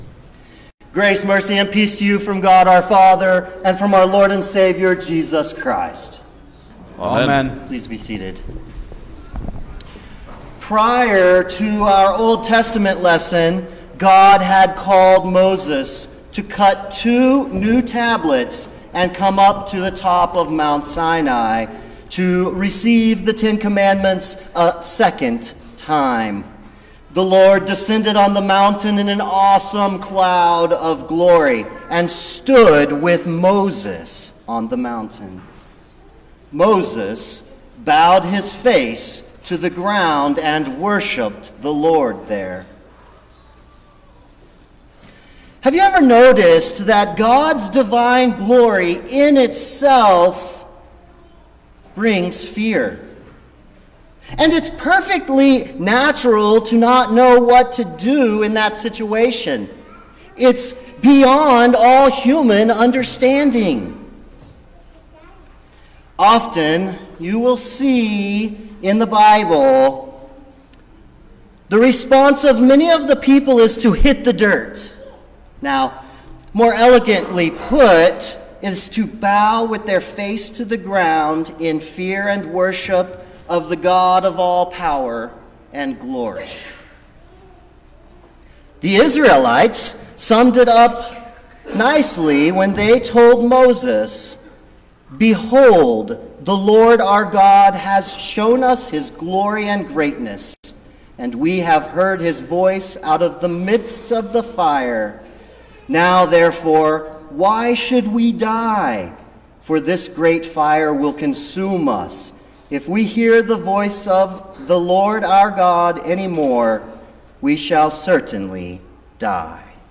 February-6_2022_The-Transfiguration-of-Our-Lord_Sermon-Stereo.mp3